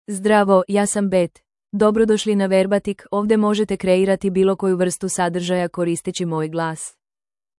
Beth — Female Serbian (Serbia) AI Voice | TTS, Voice Cloning & Video | Verbatik AI
Beth — Female Serbian AI voice
Beth is a female AI voice for Serbian (Serbia).
Voice sample
Female
Beth delivers clear pronunciation with authentic Serbia Serbian intonation, making your content sound professionally produced.